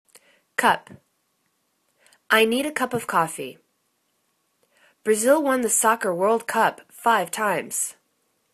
cup     /cup/    n